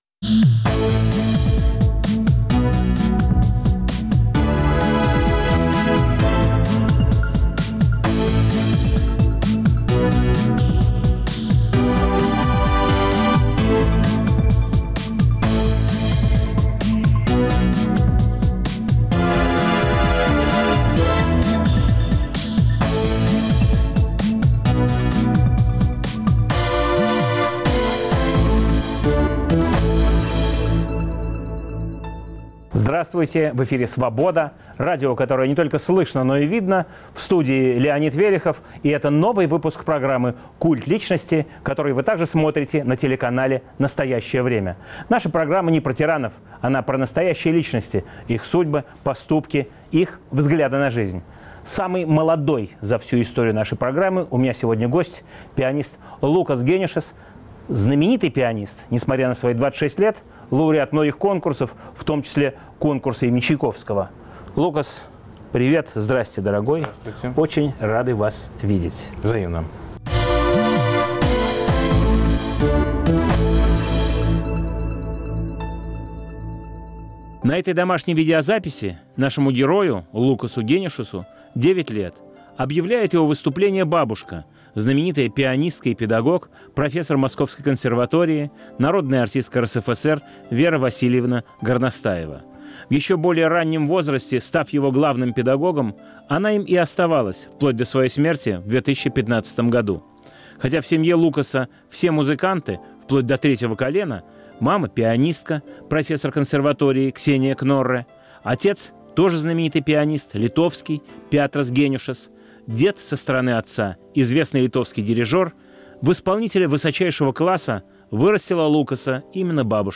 Новый выпуск программы о настоящих личностях, их судьбах, поступках и взглядах на жизнь. В студии лауреат Международного конкурса им. Чайковского, пианист Лукас Генюшас. Эфир в субботу в 18 часов 05 минут Ведущий - Леонид Велехов.